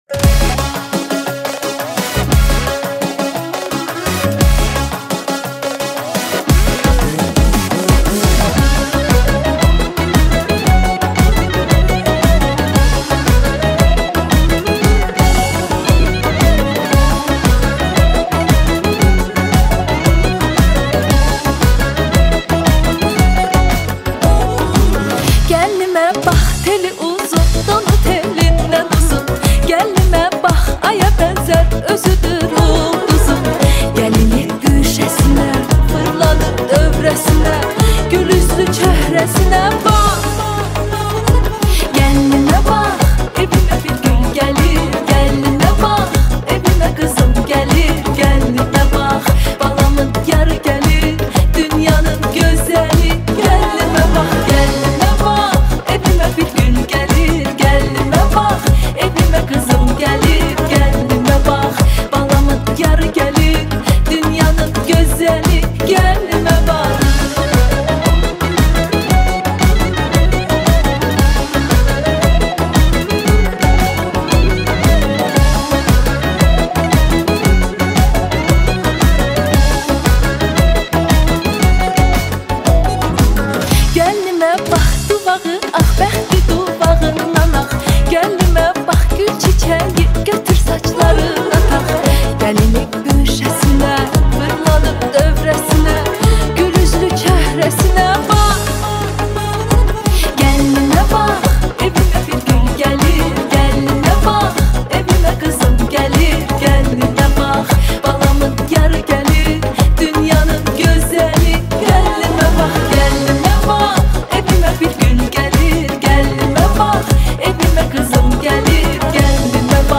آهنگ شاد آذری
آهنگ شاد ترکی مخصوص عروسی